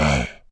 spawners_mobs_mummy_hit.4.ogg